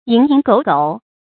注音：ㄧㄥˊ ㄧㄥˊ ㄍㄡˇ ㄍㄡˇ
蠅營狗茍的讀法